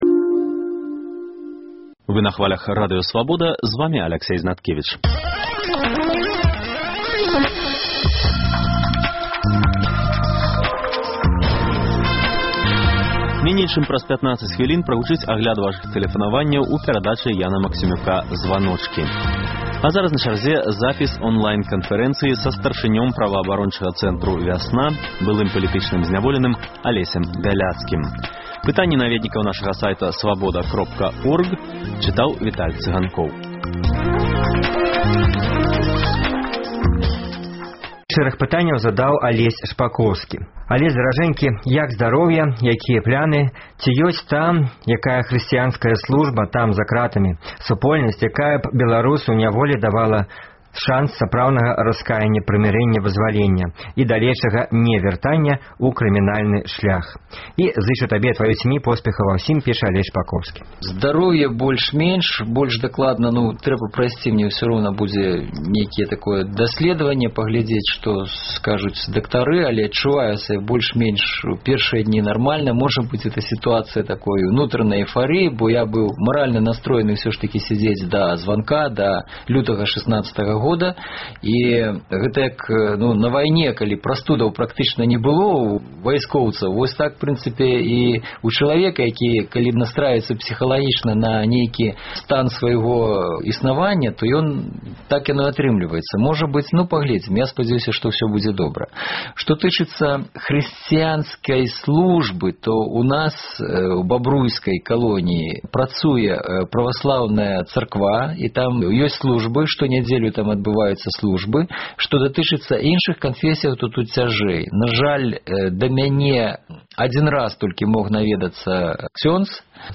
Онлайн канфэрэнцыя з праваабаронцам, былым палітвязьнем Алесем Бялцкім.